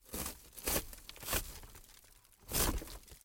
Хруст и разрушение лобового стекла авто
Тут вы можете прослушать онлайн и скачать бесплатно аудио запись из категории «Удары, разрушения».